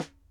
Drum Samples
S n a r e s